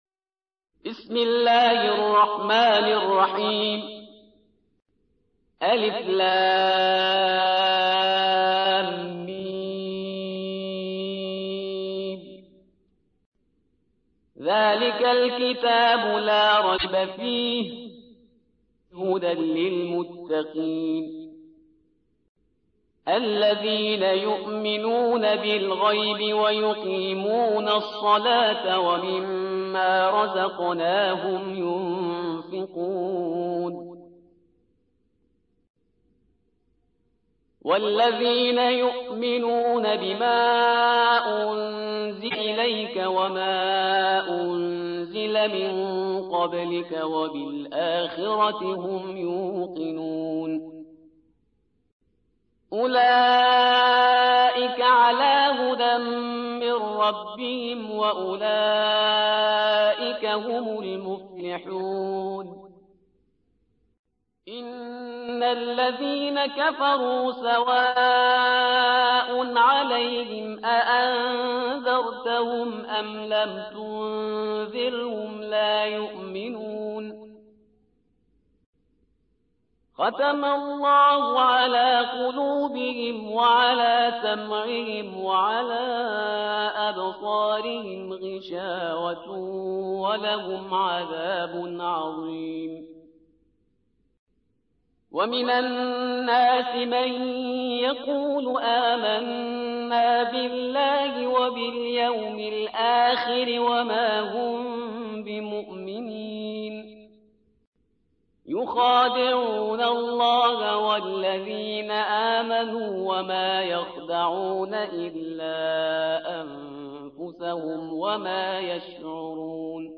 2. سورة البقرة / القارئ